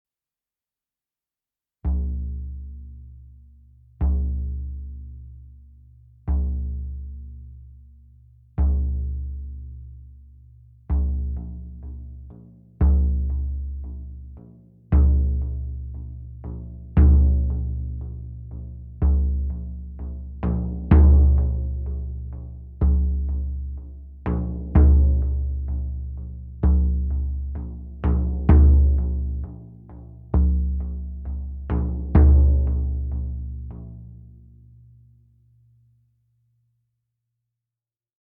Meinl Sonic Energy Moon Phases Ritual Drum 20" - True Feel Synthetic Head (RD20DWB-SH)